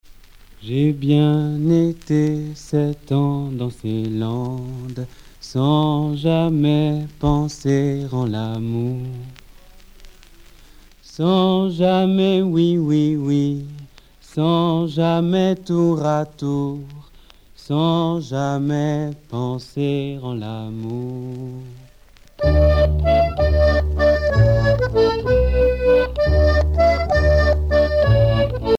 danse : valse
Genre strophique
Pièce musicale éditée